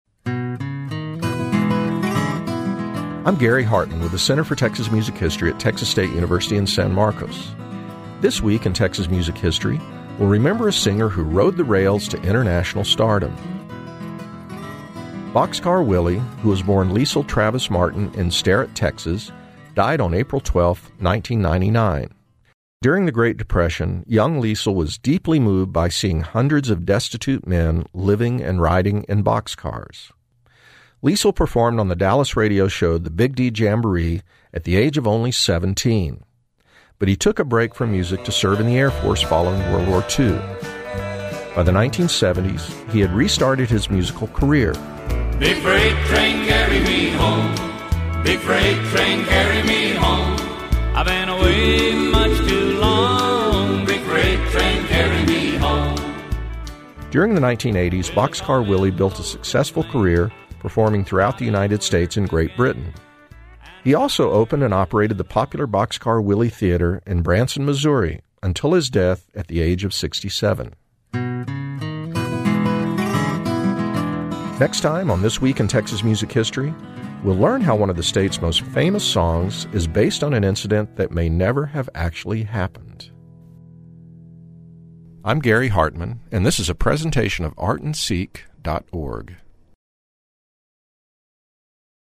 Texas music scholar